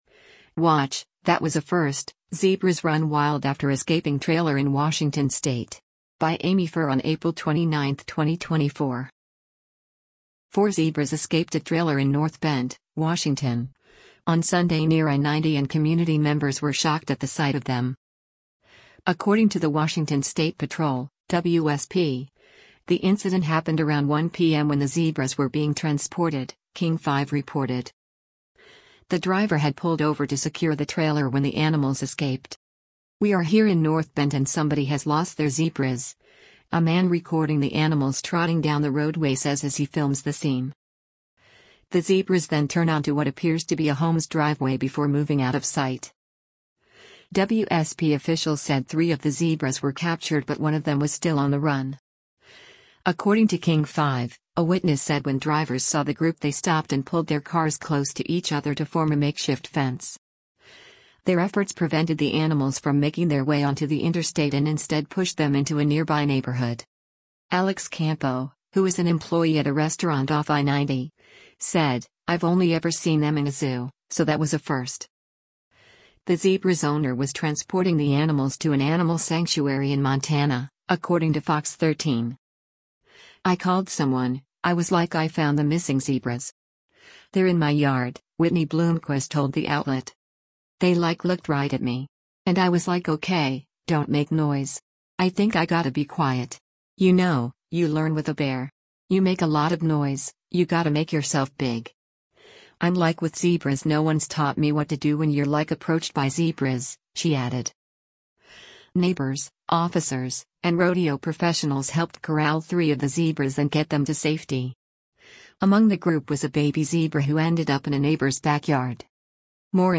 “We are here in North Bend and somebody has lost their zebras,” a man recording the animals trotting down the roadway says as he films the scene.